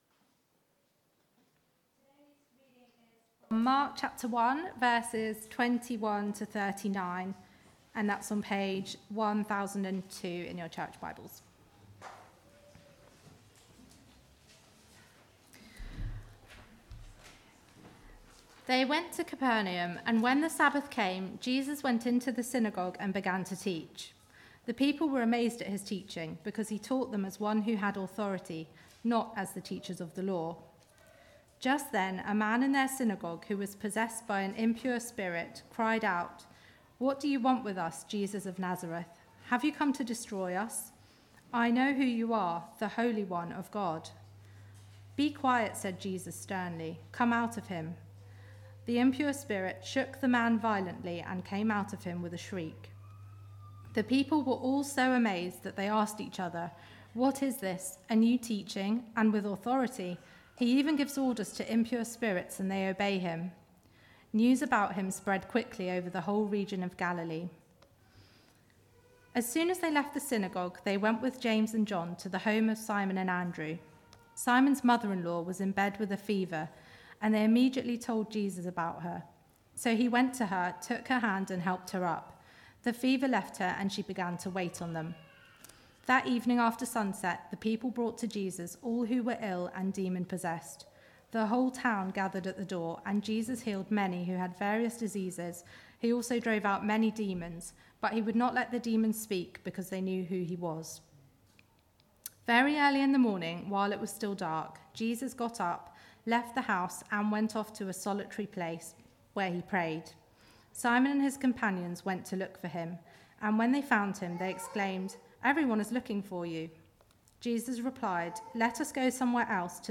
Sermon-8th-May-2022.mp3